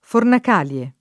vai all'elenco alfabetico delle voci ingrandisci il carattere 100% rimpicciolisci il carattere stampa invia tramite posta elettronica codividi su Facebook Fornacalie [ fornak # l L e ] n. pr. f. pl. — antica festa romana